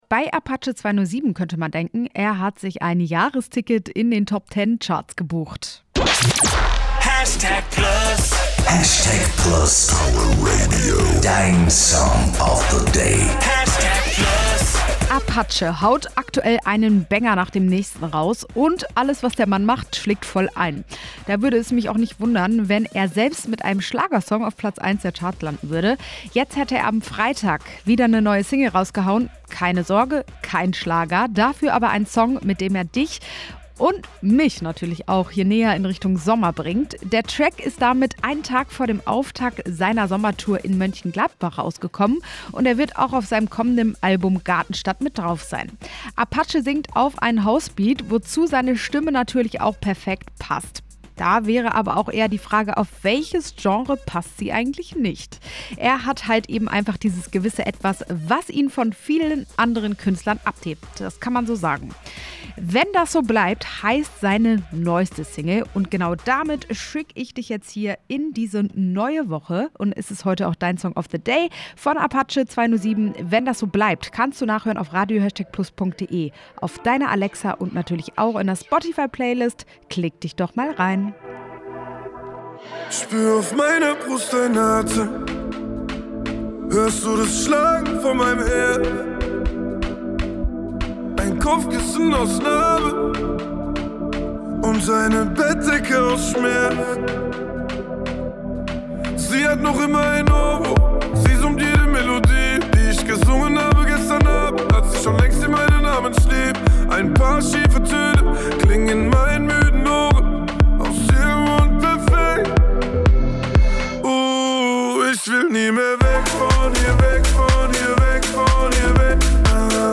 House Beat